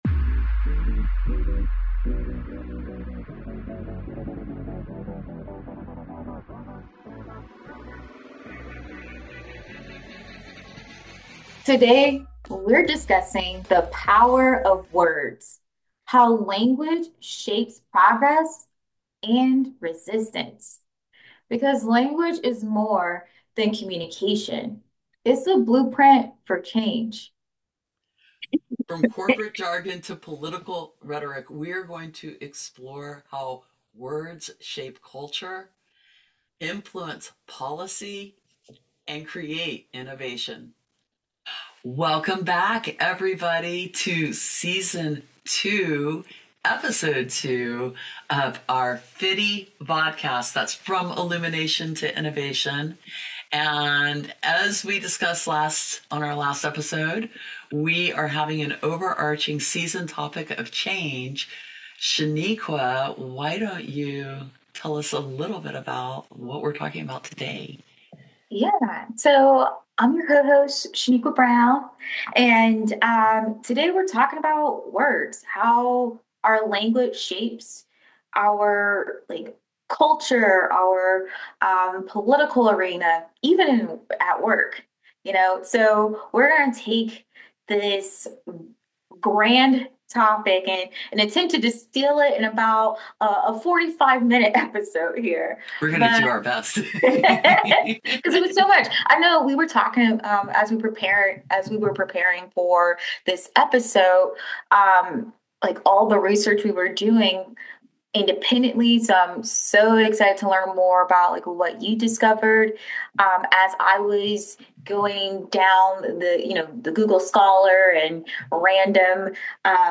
We unpack how our words shape culture, policy, connection, and control. Elevating insights inspired by linguists, neuroscientists, and everyday experiences, this conversation reveals why language is one of the most potent tools for transformation.